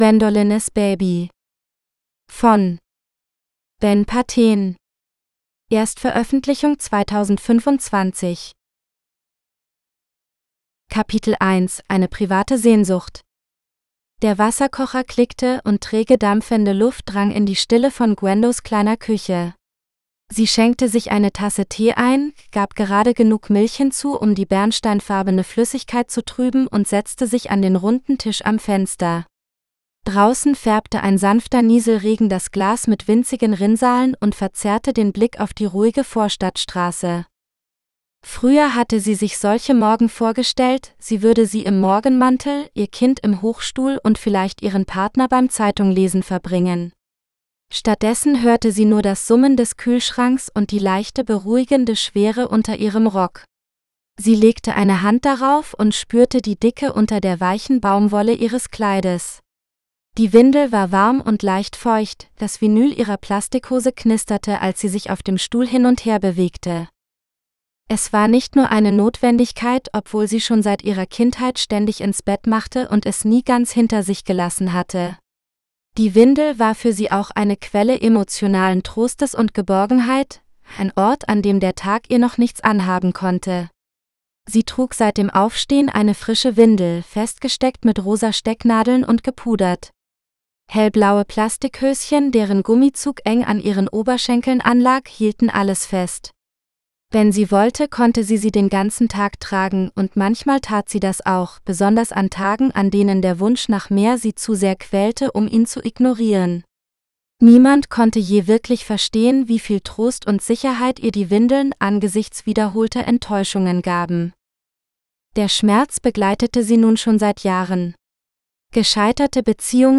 Gwendolines Baby GERMAN (AUDIOBOOK – female): $US5.75